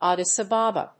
音節Ad・dis A・ba・ba 発音記号・読み方
/ˈædɪsˈæbəbə(米国英語), ˈɑ:dɪsʌˈbɑ:bɑ:(英国英語)/